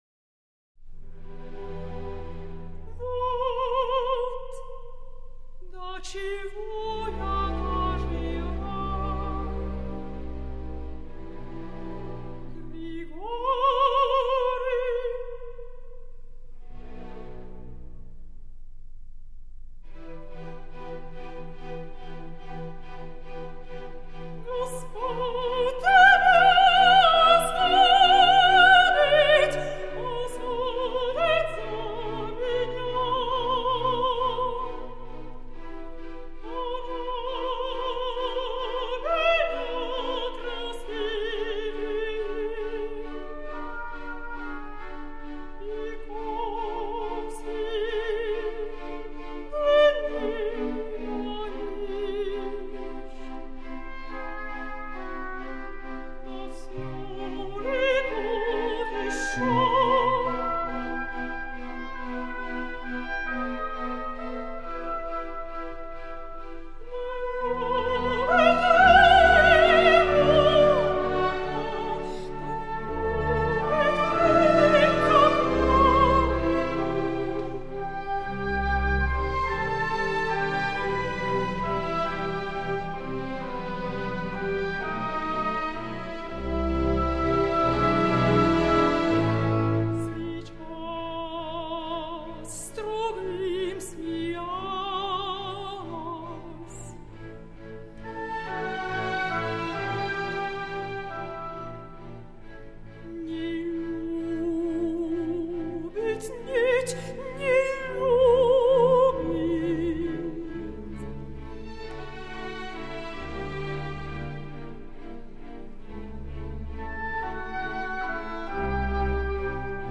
мецо сопрано